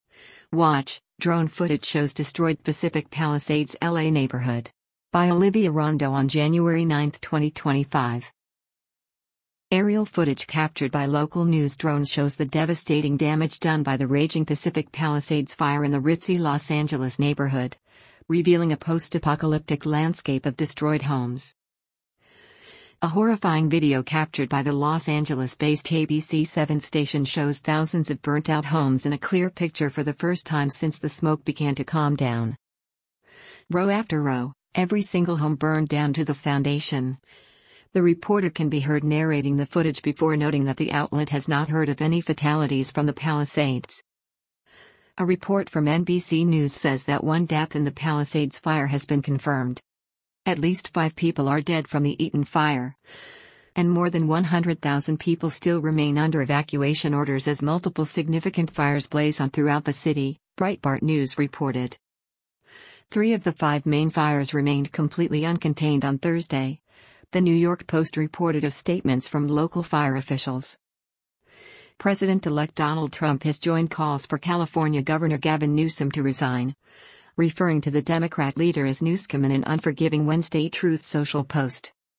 “Row after row, every single home burned down to the foundation,” the reporter can be heard narrating the footage before noting that the outlet has not heard of any fatalities from the Palisades.